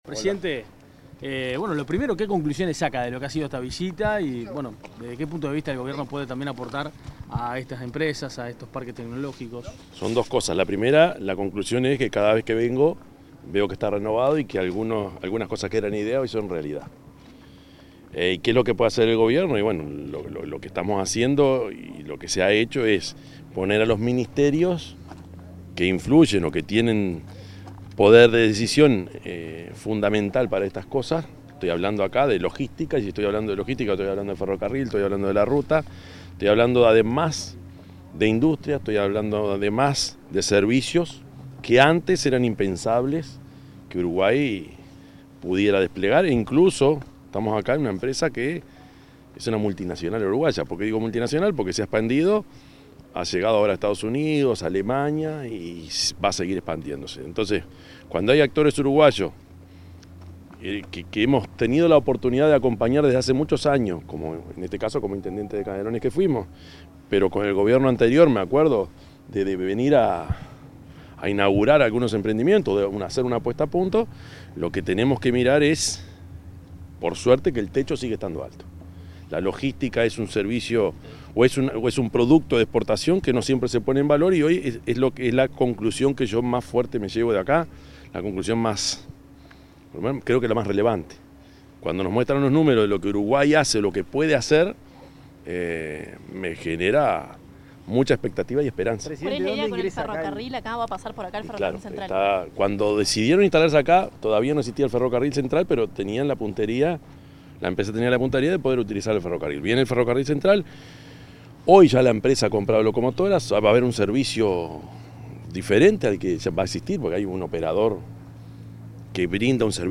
Declaraciones del presidente Orsi en recorrido por el Parque Industrial Ruta 5
Luego dialogó con la prensa.